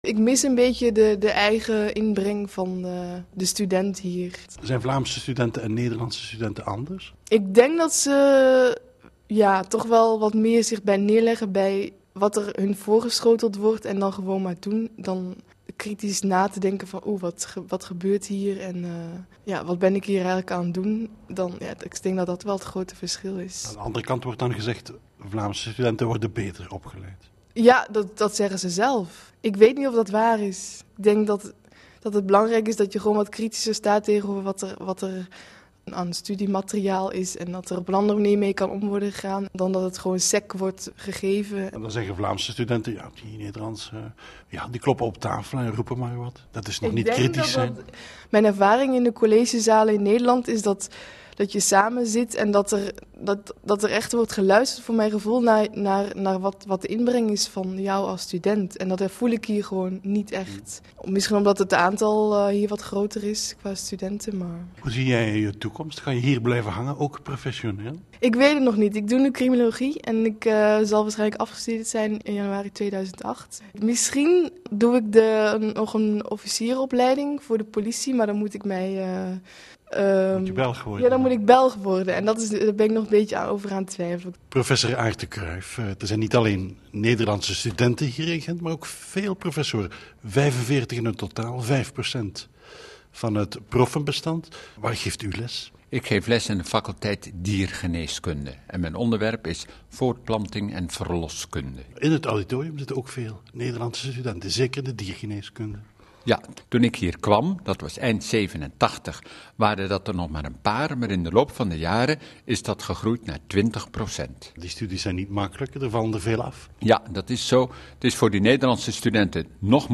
voor het interview